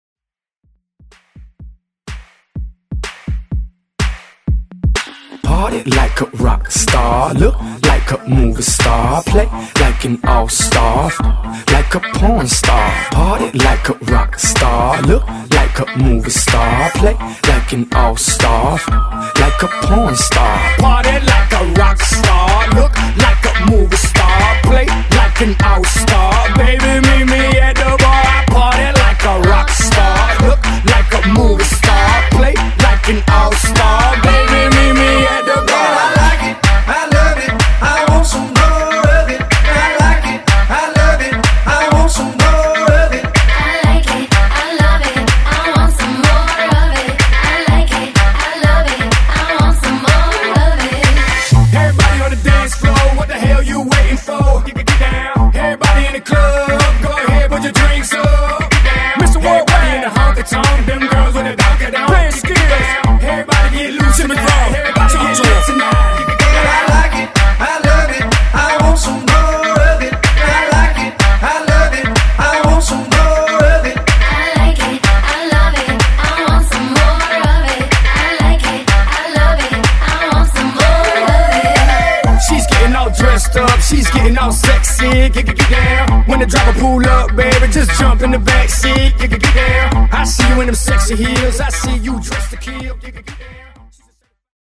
Genres: EDM , MASHUPS , TOP40
Clean BPM: 128 Time